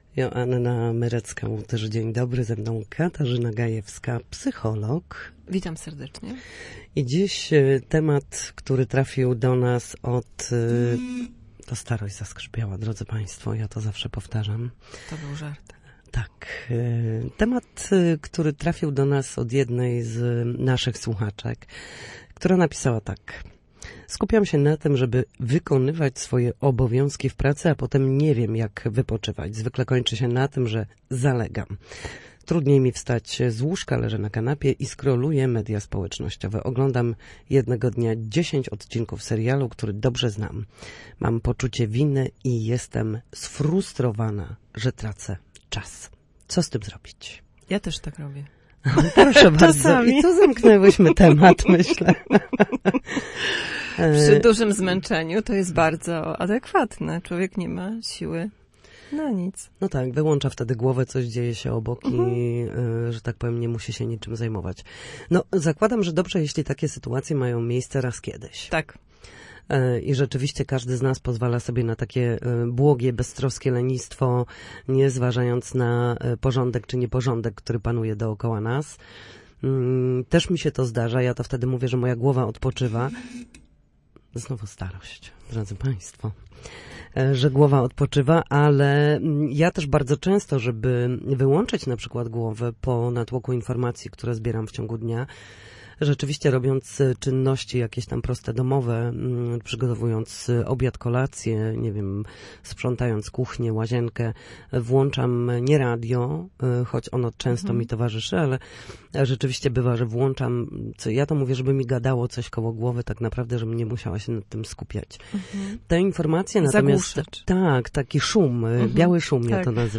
W każdą środę, w popołudniowym Studiu Słupsk Radia Gdańsk dyskutujemy o tym, jak wrócić do formy po chorobach i urazach.